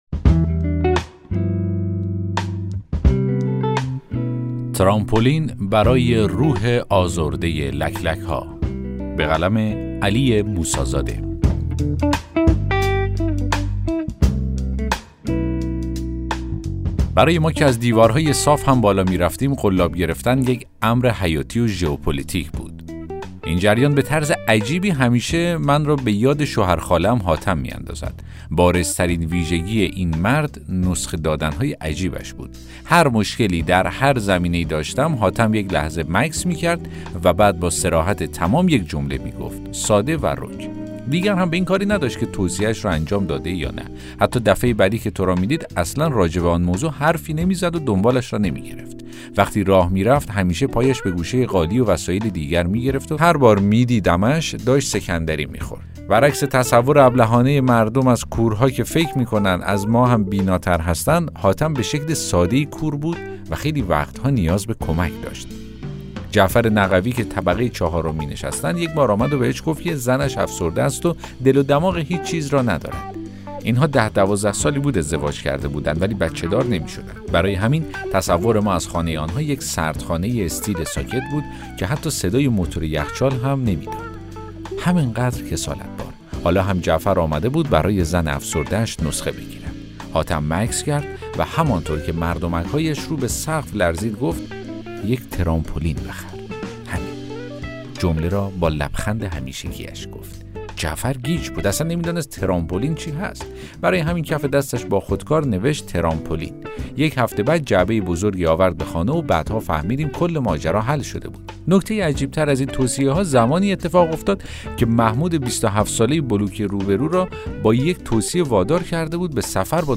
داستان صوتی: ترامپولین برای روح آزرده لک لک‌ها